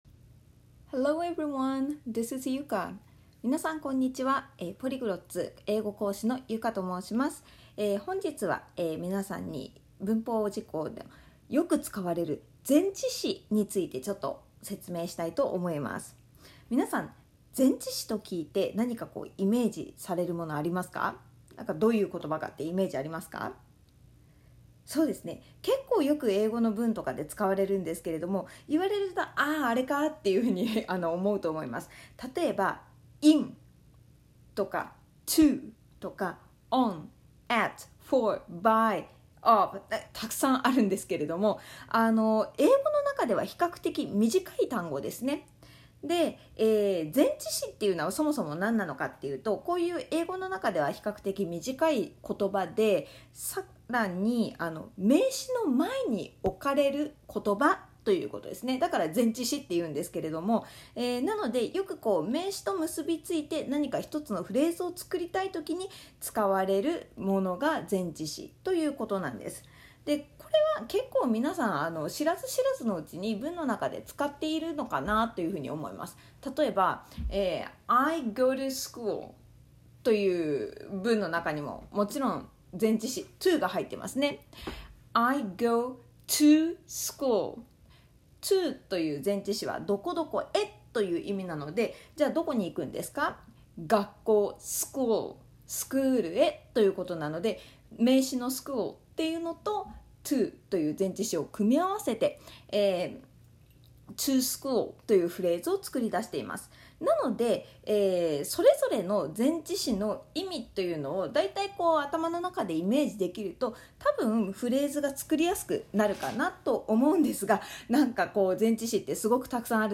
大人のための英語学習ファーストステップ！英会話BuildUPトレーニング | 予習用 文法解説音声ページ